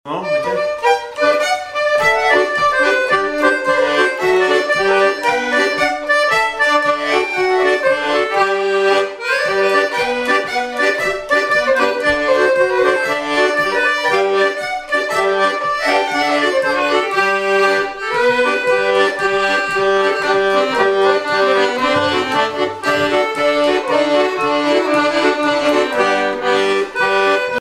danse : quadrille : poule ;
Répertoire de bal au violon et accordéon
Pièce musicale inédite